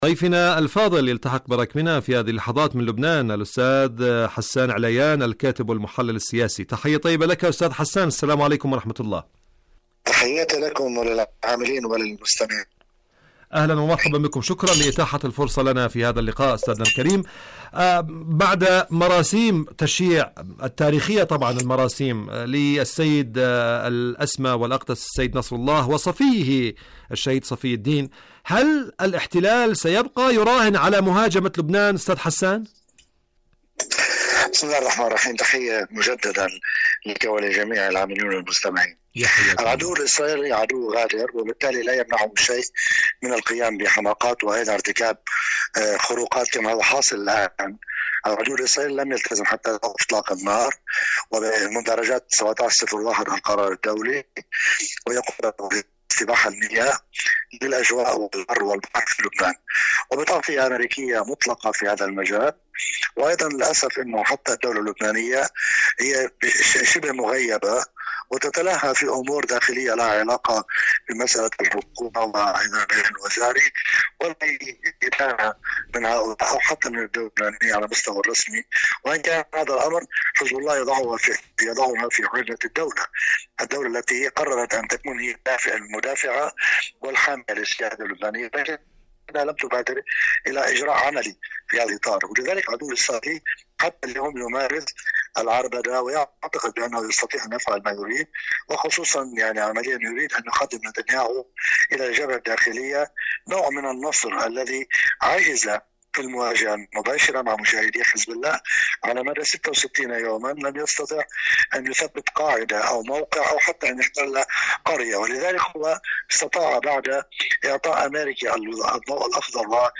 مقابلات إذاعية